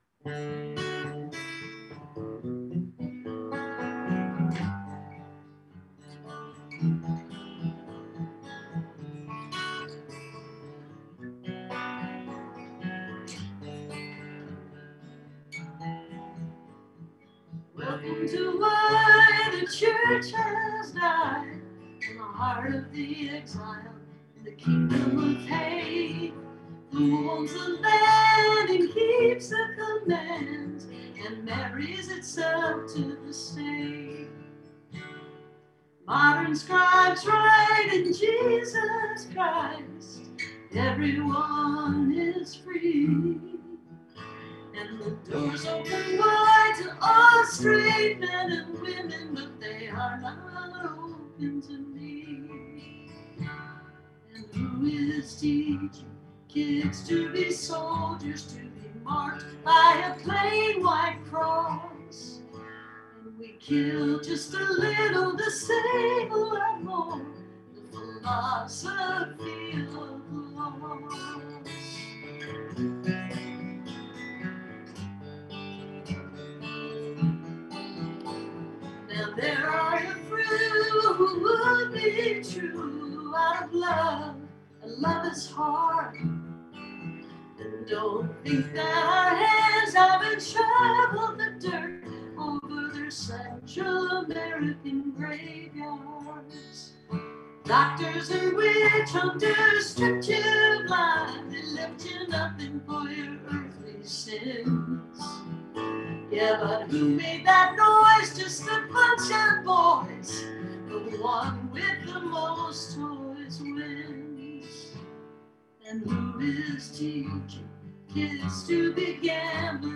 (captured from the zoom livestream)